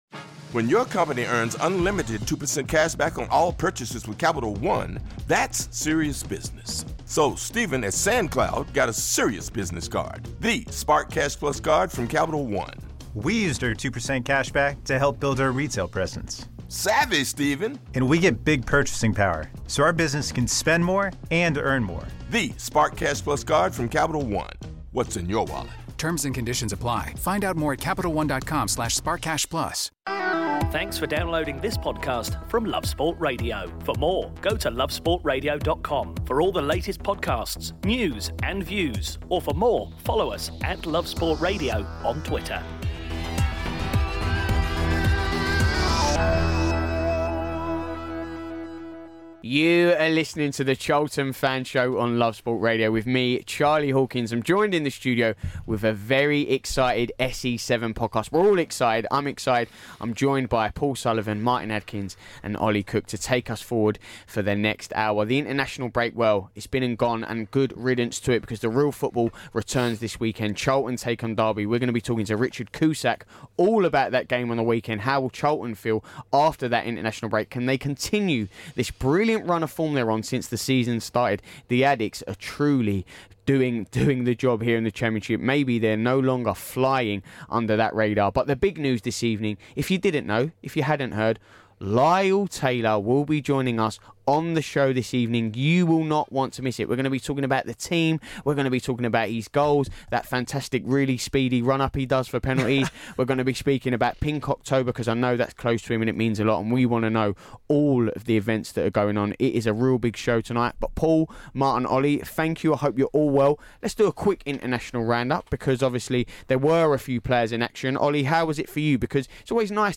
a look ahead to the games coming up and they are joined by Charlton player Lyle Taylor on the phone